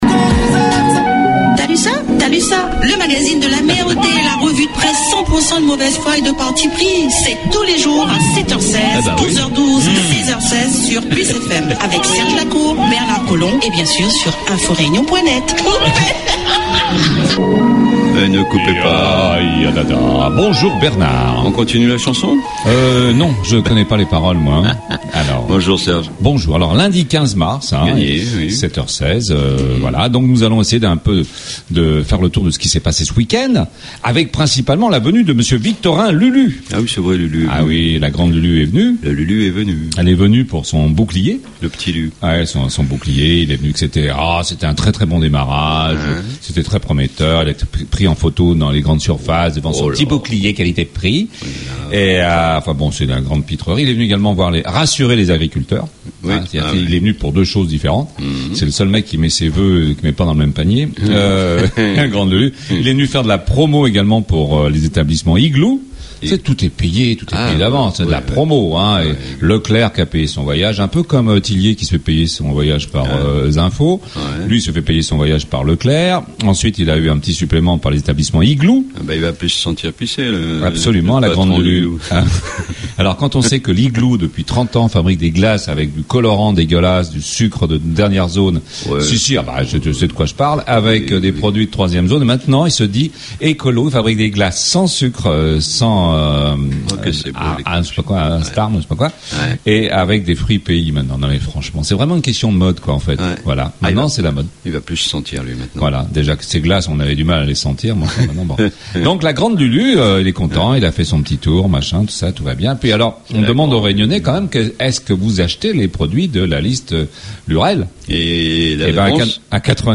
REVUE DE PRESSE DU JOUR, THALUSSA le magazine de l'amer du lundi 15 avril 2013